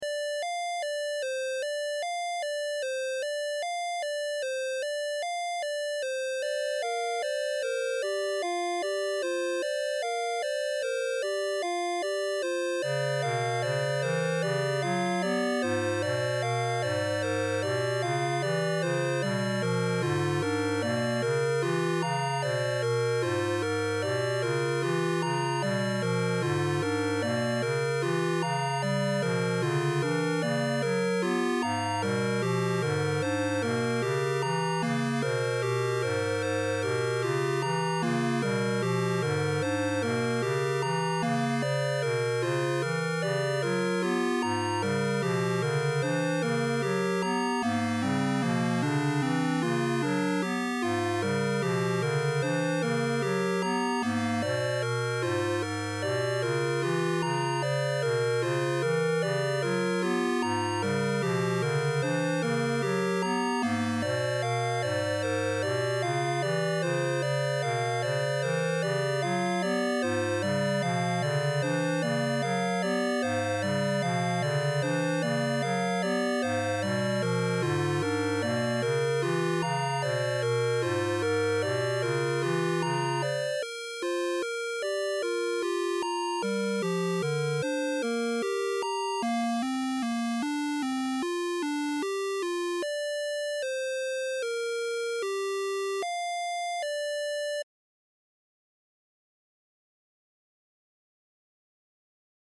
The Beginning - 8-Bit music